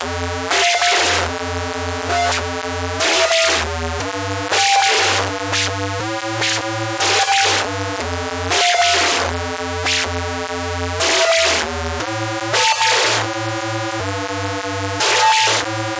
描述：Pizzi风格的合成器与方波振荡器和噪声发生器相结合，使用LFO对峰值进行调制，给人以突变的感觉。
Tag: 120 bpm Glitch Loops Synth Loops 2.69 MB wav Key : C